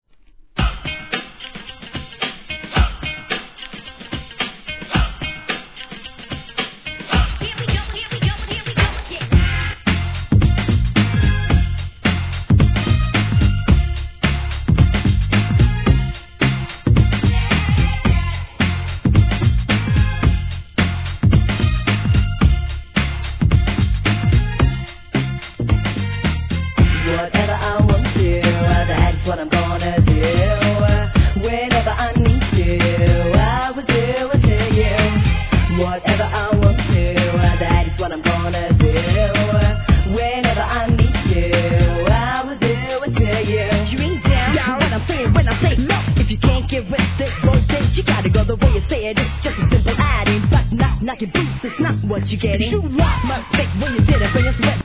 HIP HOP/R&B
1994年、アップテンポのインディーR&B!! 怒キャッチーなRAPもテンション↑